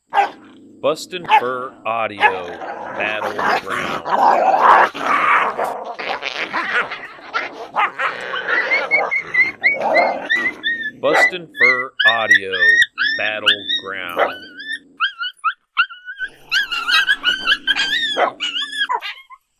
Aggressive territorial fight between two adult coyotes. Lots of growling and squalling in this sound file.